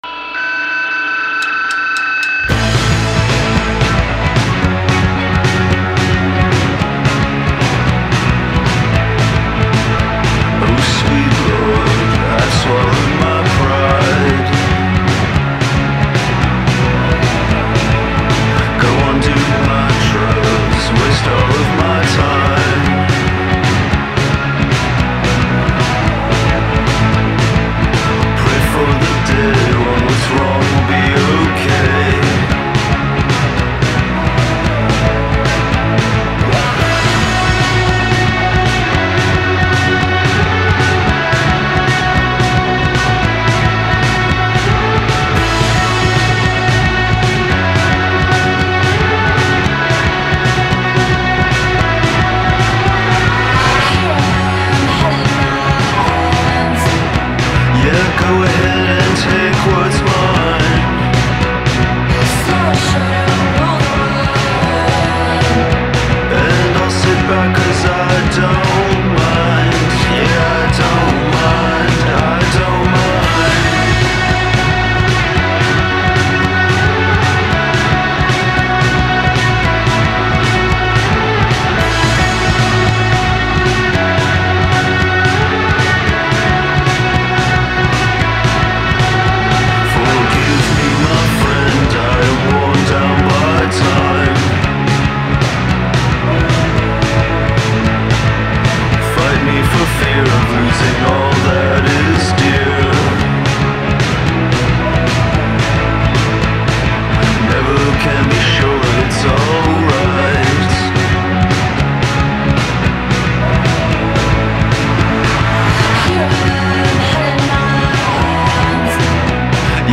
sounds like all harakiri diat bands combined into one